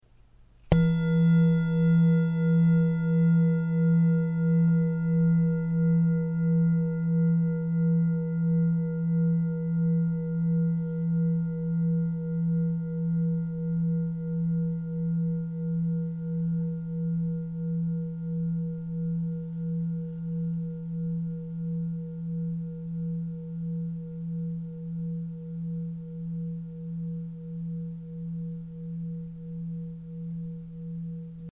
Tibetische Klangschale - UNIVERSALSCHALE
Durchmesser: 19,5 cm
Grundton: 167,05 Hz
1. Oberton: 479,12 Hz